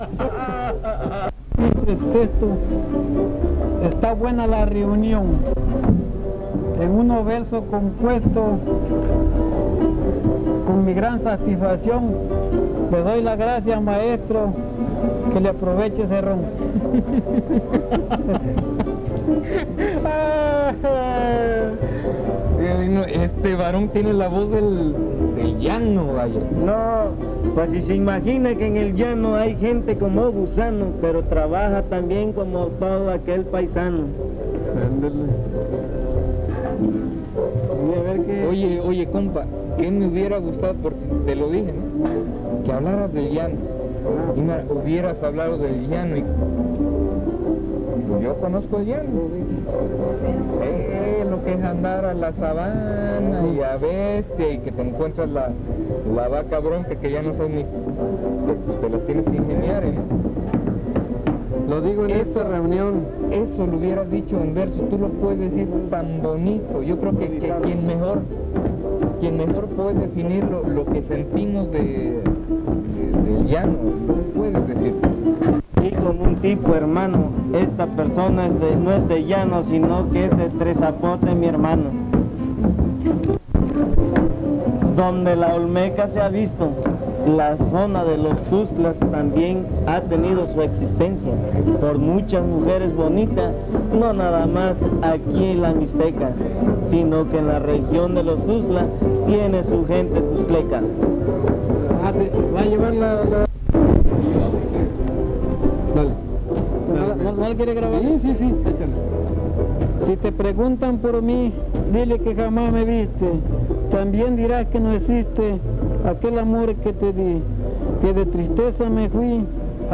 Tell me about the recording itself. Concurso Estatal de Fandango